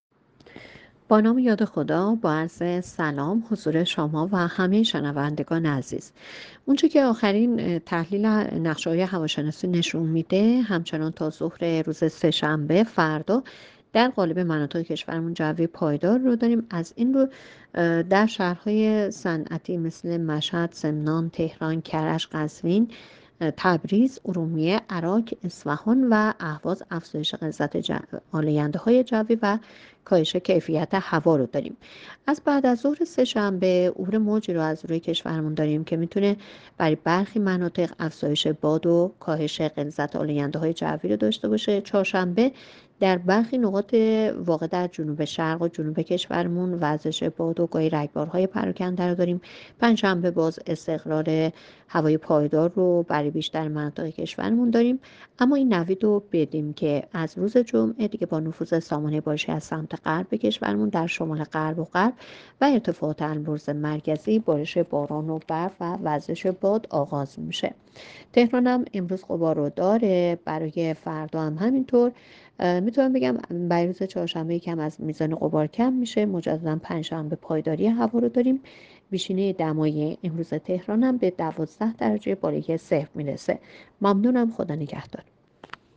گزارش رادیو اینترنتی پایگاه‌ خبری از آخرین وضعیت آب‌وهوای۳ دی؛